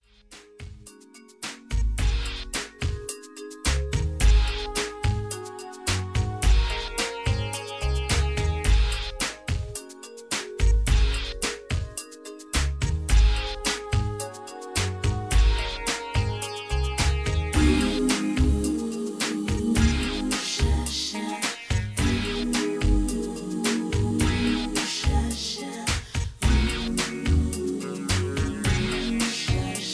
(Version-3, Key-Ab) Karaoke MP3 Backing Tracks